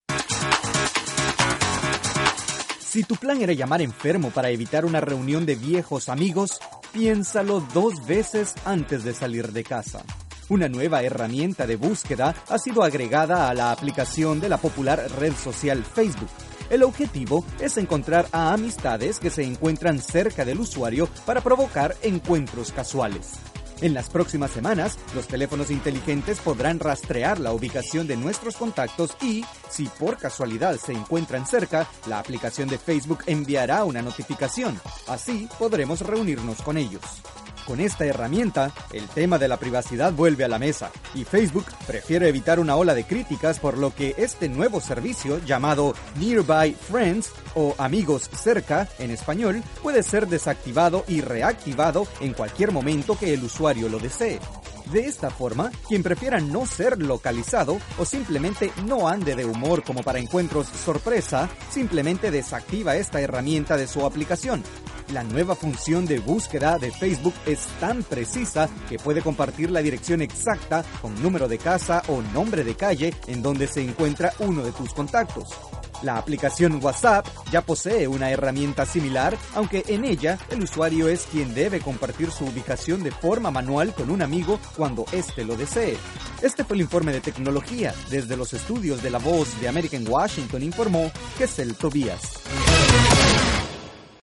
La aplicación para teléfonos inteligentes de la red social Facebook, permite ahora que sea más fácil localizar a nuestros amigos cuando se encuentren cerca a nuestra ubicación. Desde los estudios de la Voz de América en Washington informa